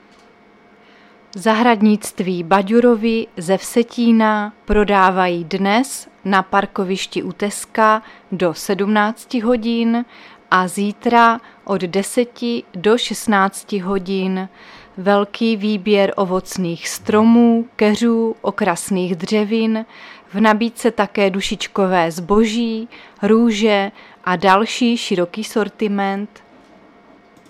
Záznam hlášení místního rozhlasu 1.11.2024